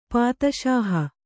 ♪ pātaṣāha